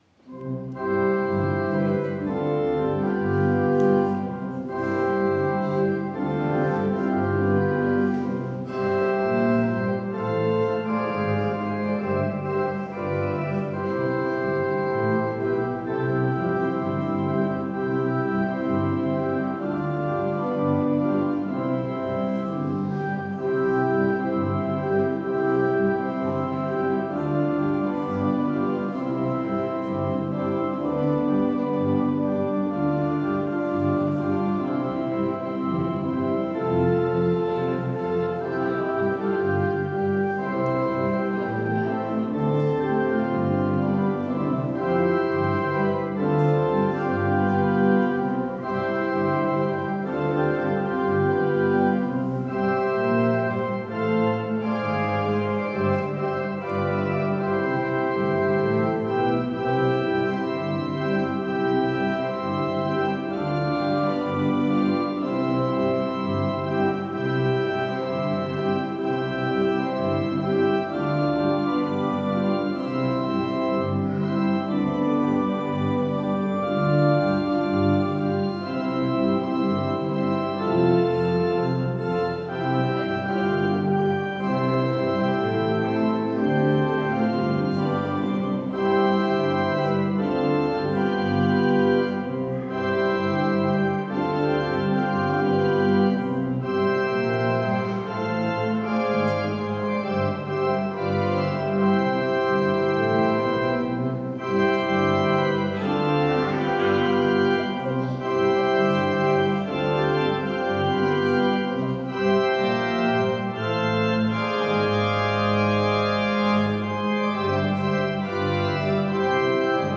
Orgelstück zum Ausgang Ev.-Luth.
Audiomitschnitt unseres Gottesdienstes vom 4.Advent 2025.